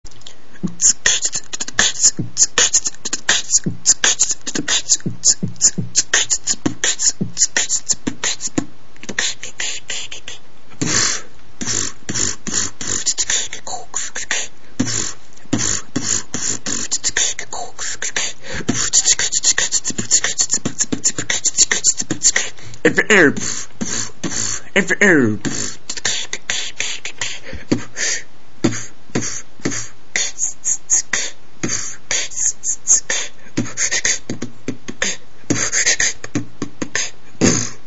Форум российского битбокс портала » Реорганизация форума - РЕСТАВРАЦИЯ » Выкладываем видео / аудио с битбоксом » Оцените плз
Бит чистый фристаил, заранее ничего не готовил просто включил микро и записал, сразу извиняюсь за скреч он просто ужасен)